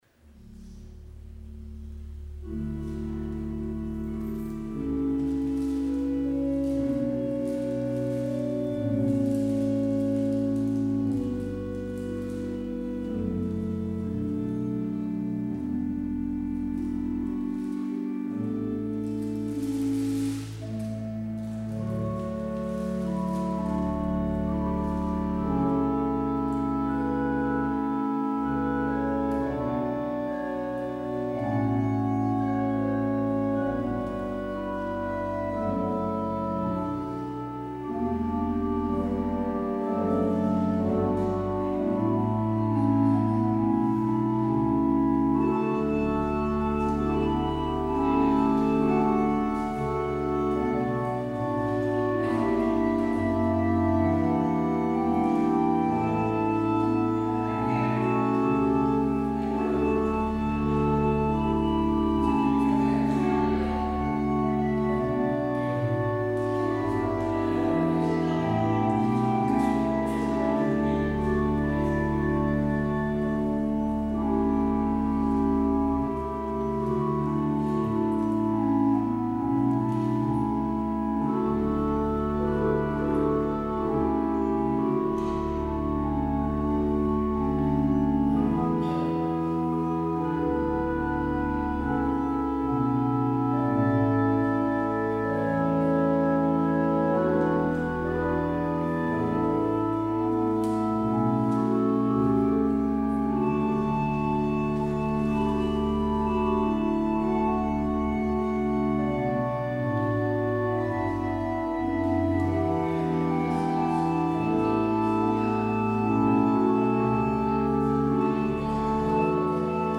 Luister deze kerkdienst hier terug
Als openingslied, Psalm 42:1,6,7 LB ‘Evenals een moede hinde’. Het slotlied is: Lied 942:1,2,3 LB ‘Ik sta voor U in leegte en gemis’.